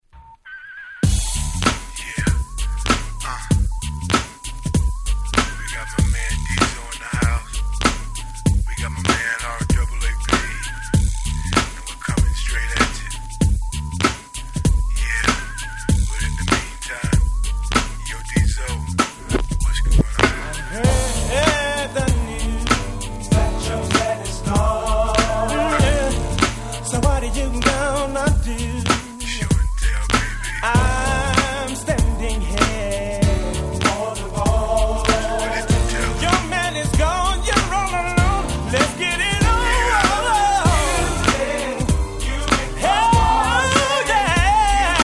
94' Nice Hip Hop Soul / R&B !!